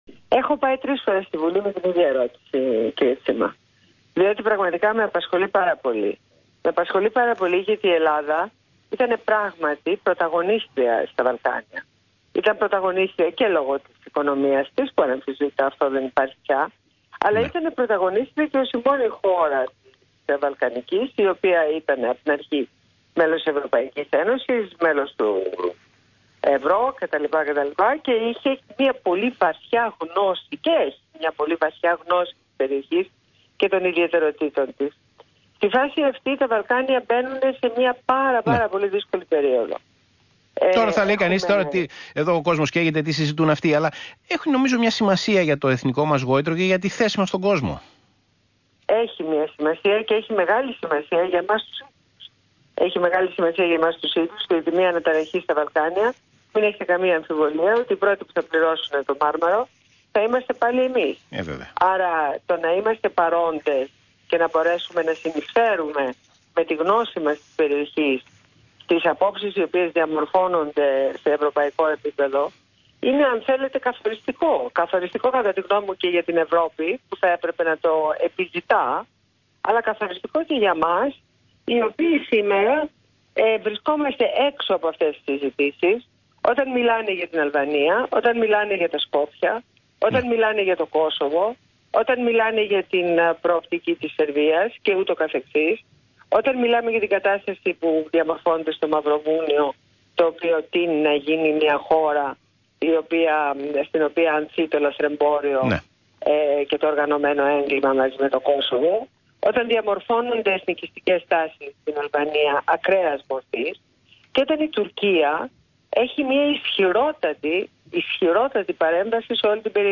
Συνέντευξη στο ραδιόφωνο του ΣΚΑΙ